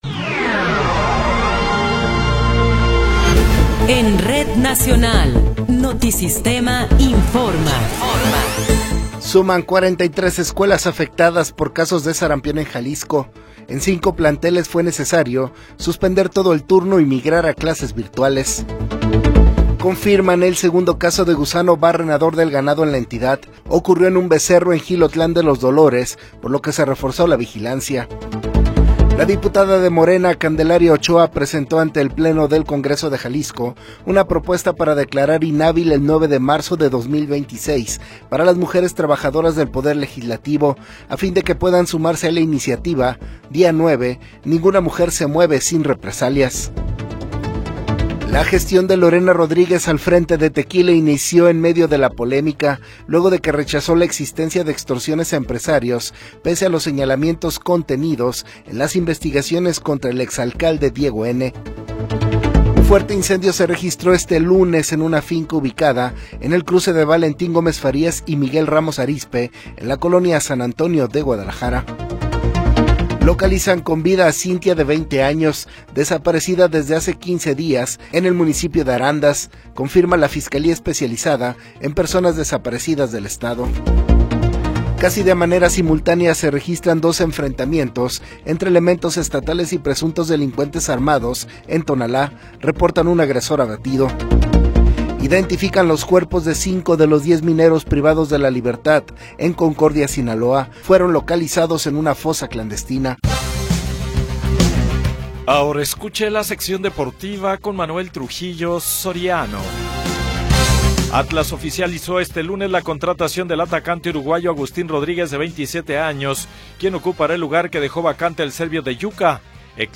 Noticiero 21 hrs. – 9 de Febrero de 2026
Resumen informativo Notisistema, la mejor y más completa información cada hora en la hora.